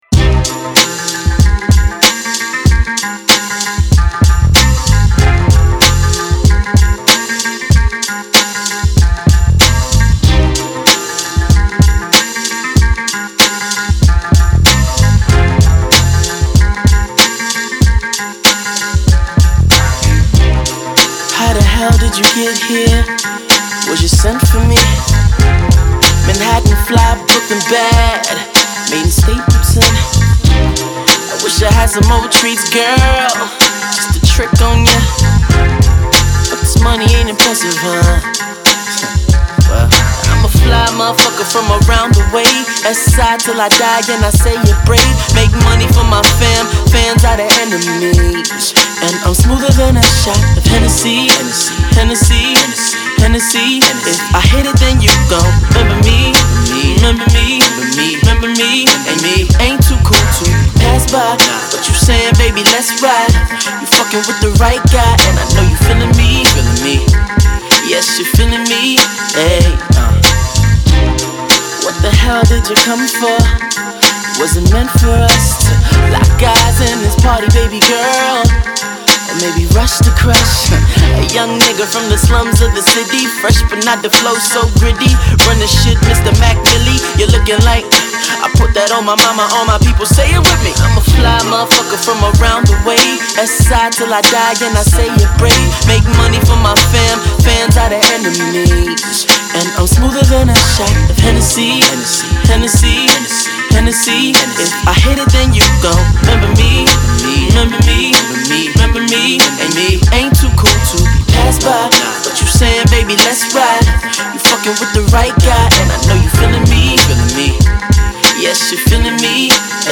Hip-Hop themed offering